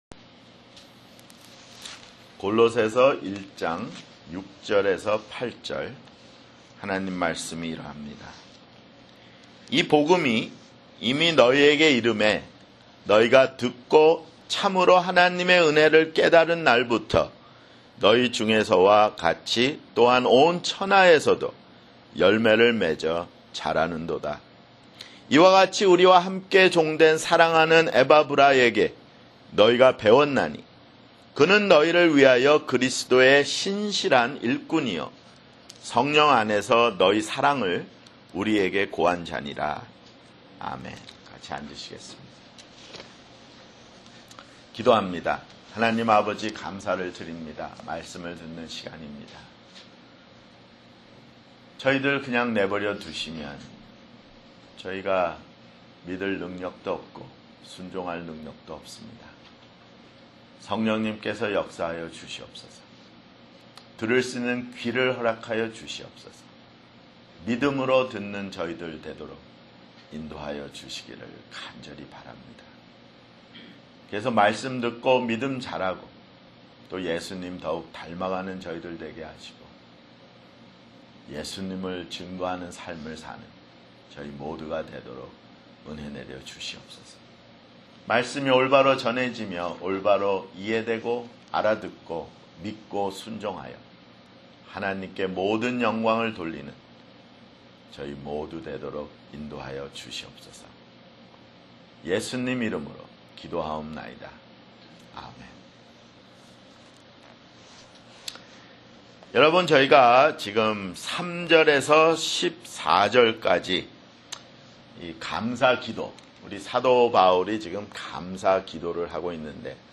[주일설교] 골로새서 (15)